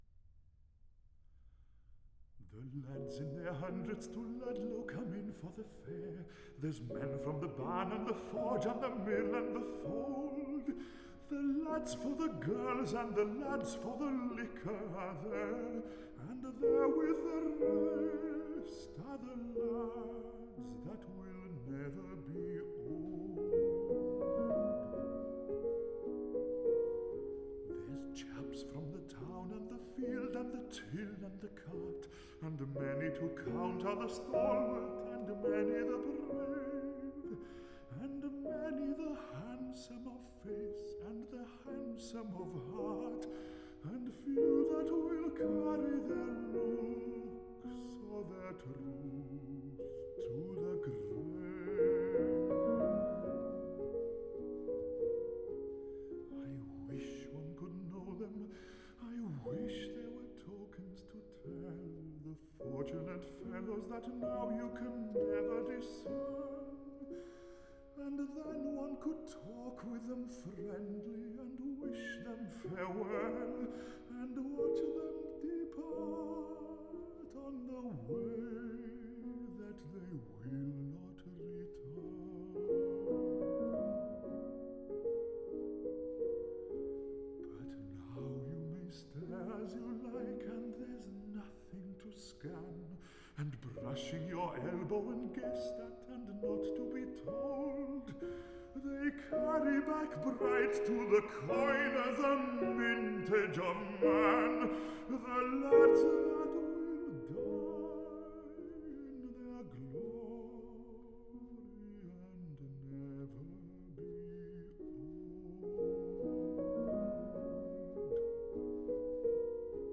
Der walisische Bass-Bariton